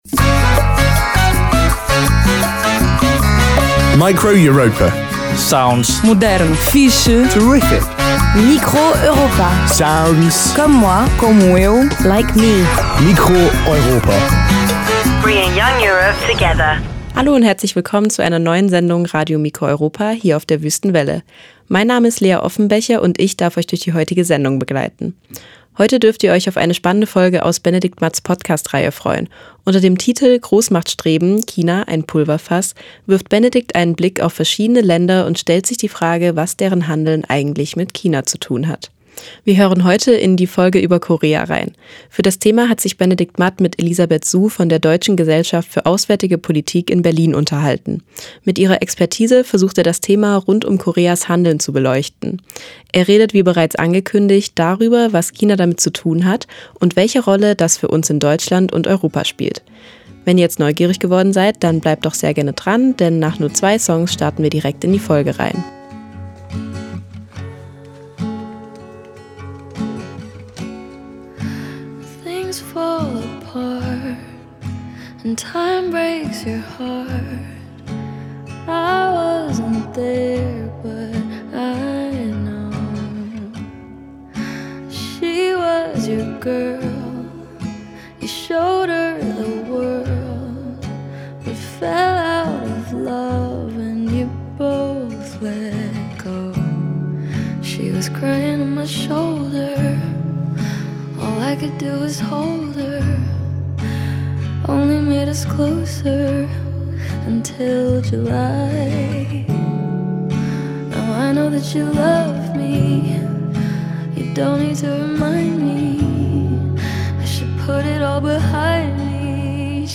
China ein Pulverfass? Korea. Feature
Form: Live-Aufzeichnung, geschnitten